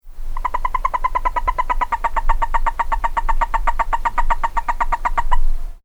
Sounds of California Toad - Anaxyrus boreas halophilus
Their call has been described as a high-pitched plinking sound, like the peeping of a chick, repeated several times.
Sound This is a recording of one repetition of the call of a California Toad recorded at night in San Diego County.